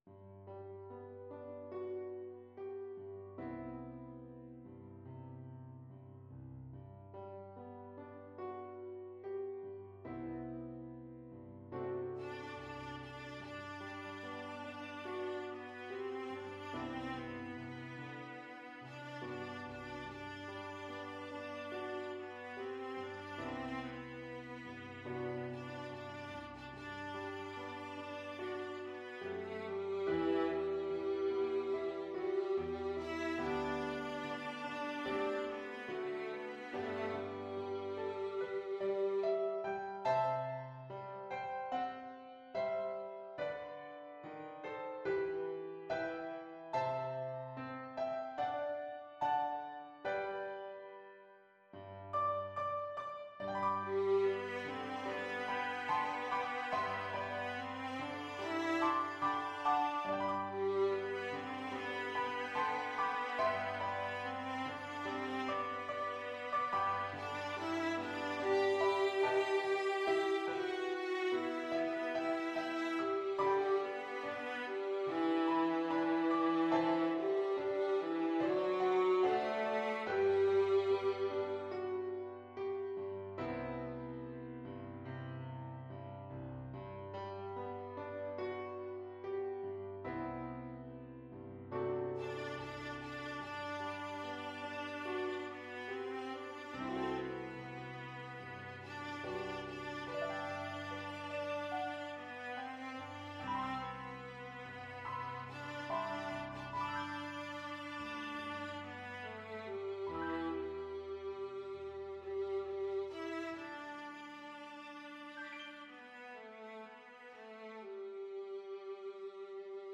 Viola
Traditional Music of unknown author.
G major (Sounding Pitch) (View more G major Music for Viola )
4/4 (View more 4/4 Music)
Molto espressivo =c.72